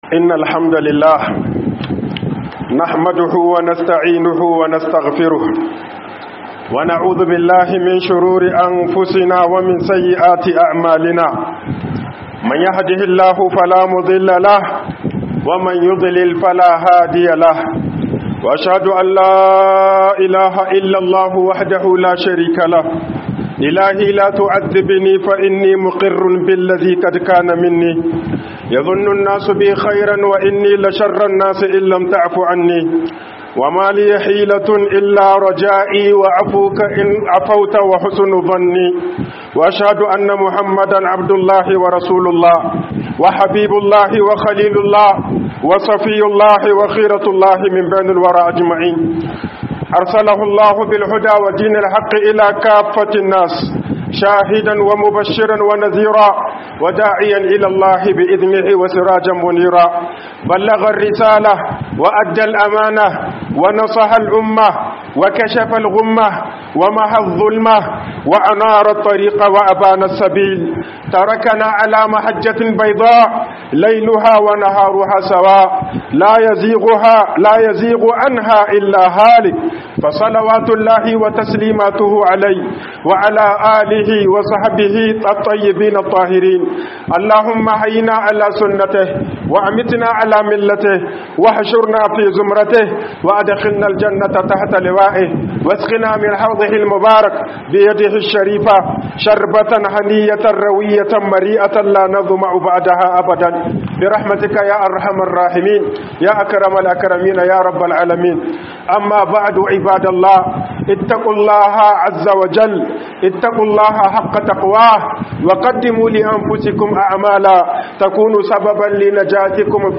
HATSARIN ZALUNCI - HUƊUBOBIN JUMA'A